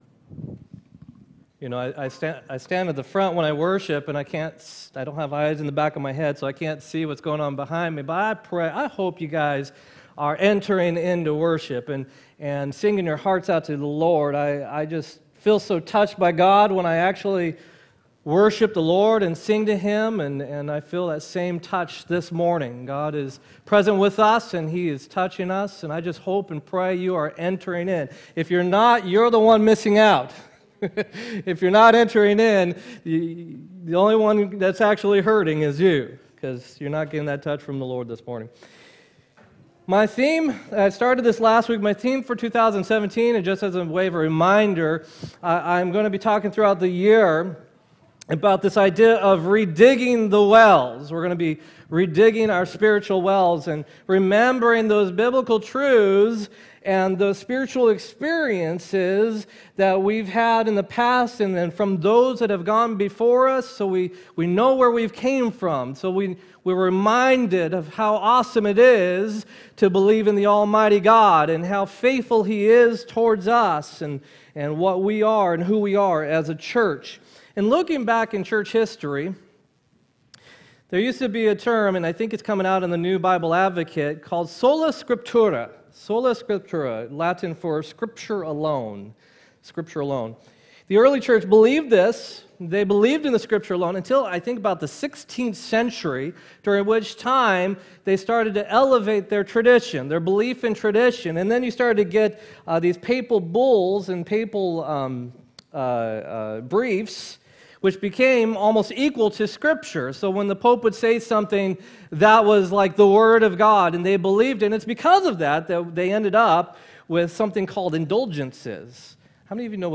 1-14-17 sermon
1-14-17-sermon.m4a